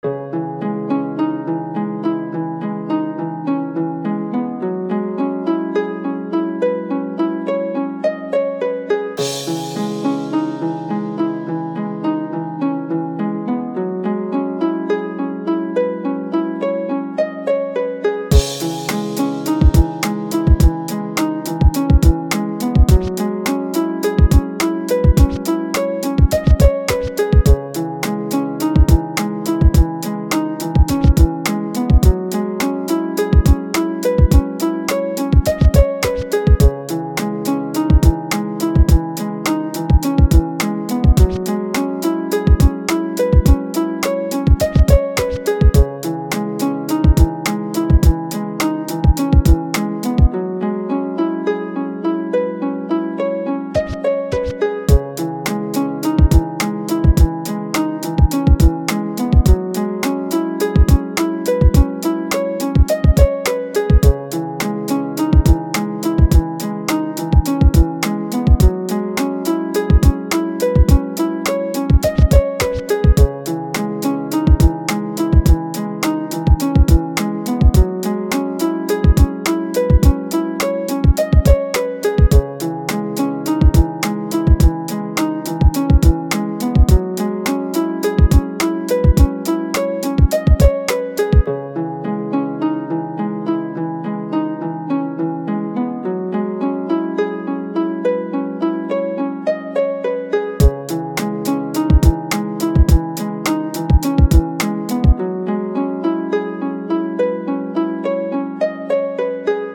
Минуса рэп исполнителей